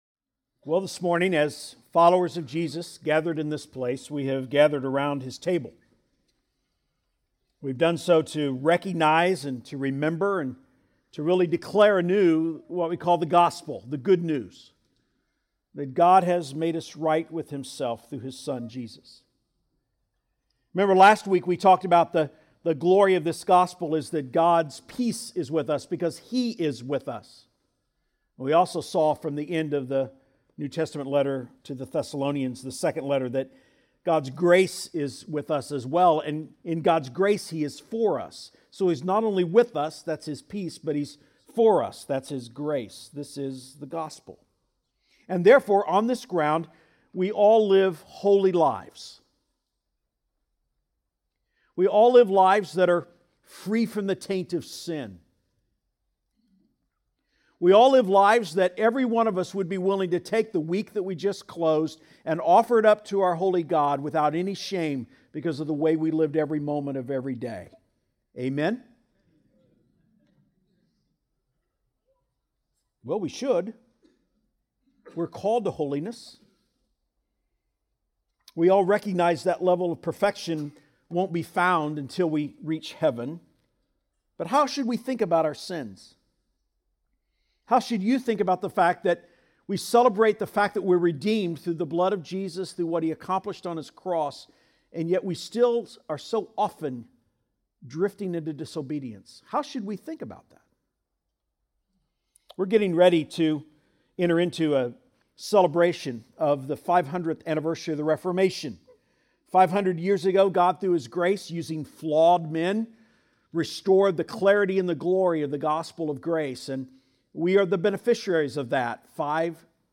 Standalone Sermons Archives - Page 3 of 5 - C3 Houston